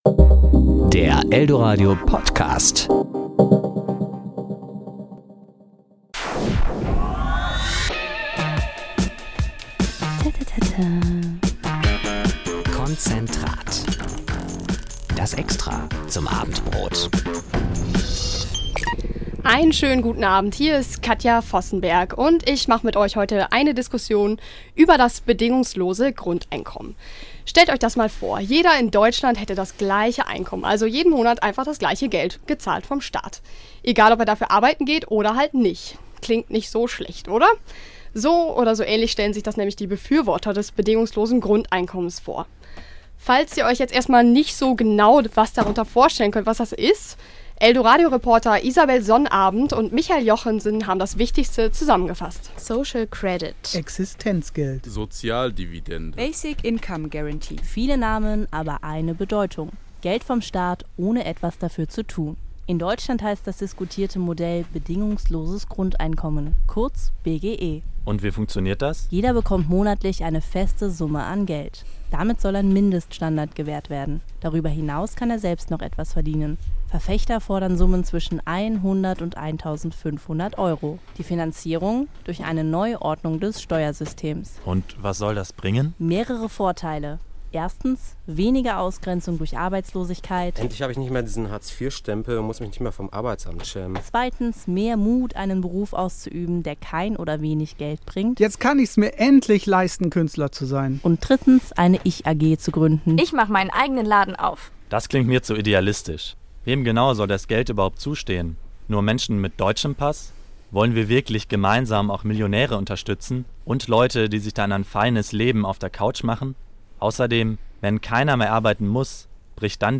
"Eldoradio"-Talksendung: Diskussion um das Bedingungslose Grundeinkommen